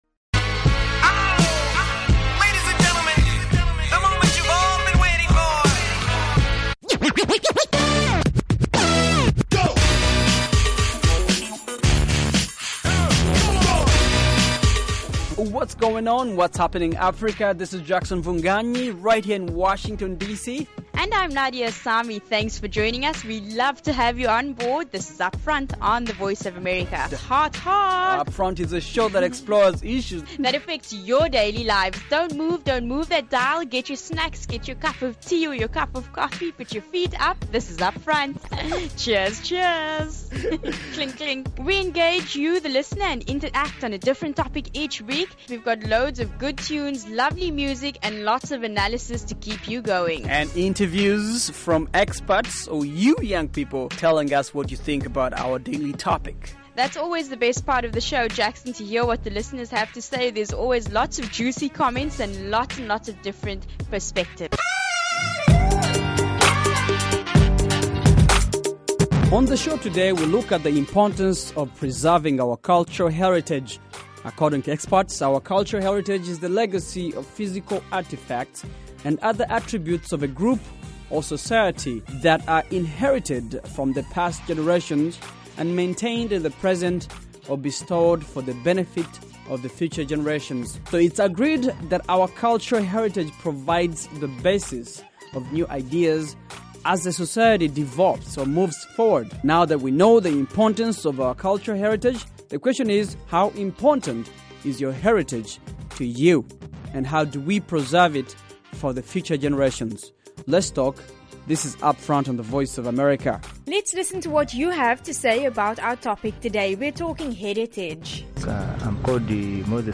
On this fresh, fast-paced show, co-hosts